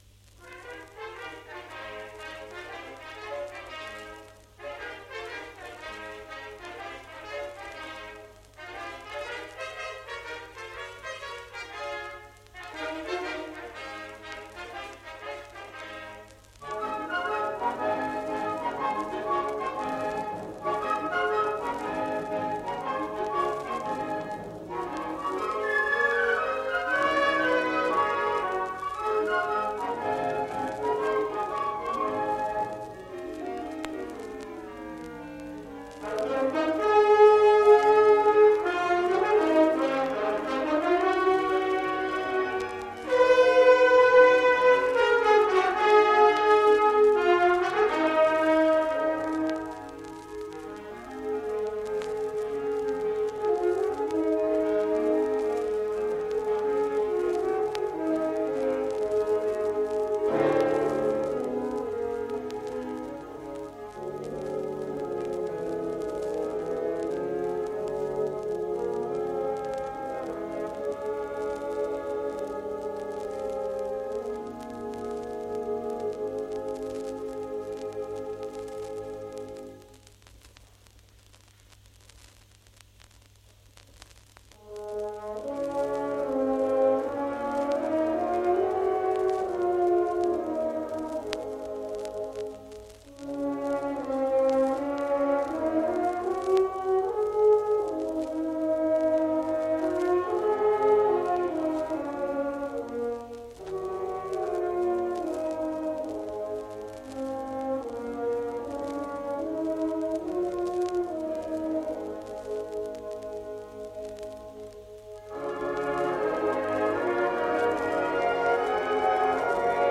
Youth Wind Ensemble